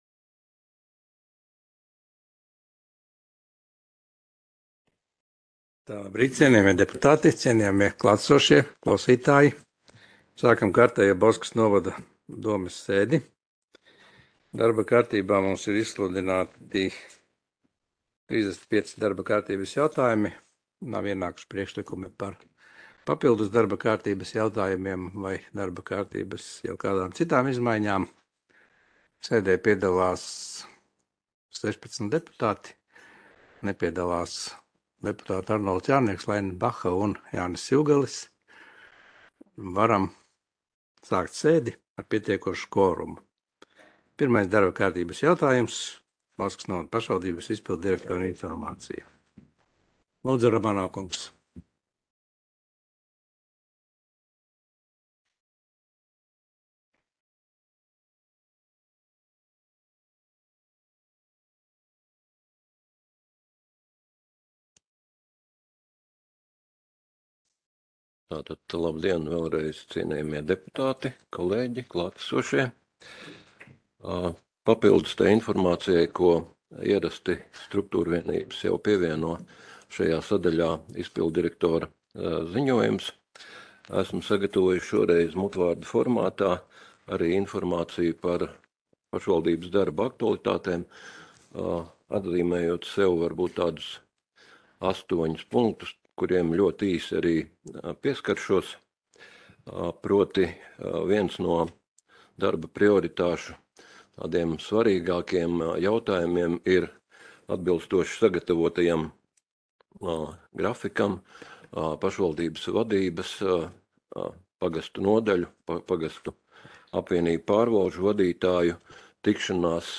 Audioieraksts - 2022.gada 27.oktobra domes sēde